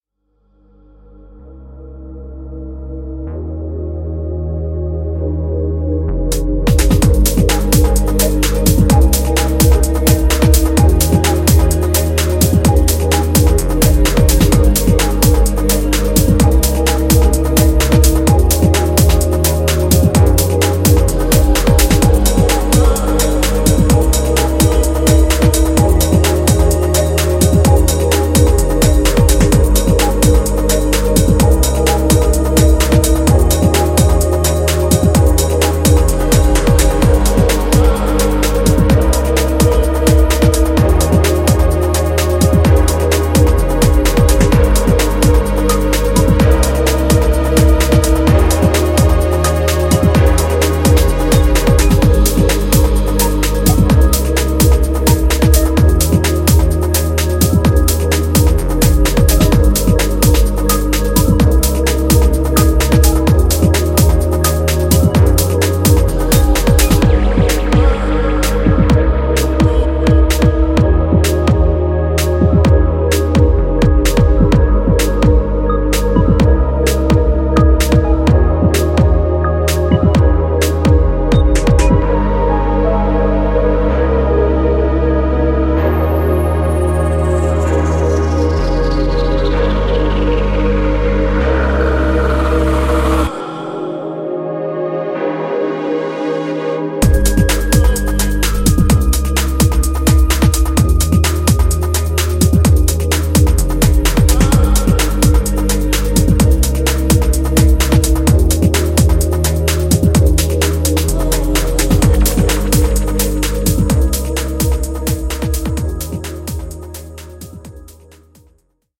classic house tracks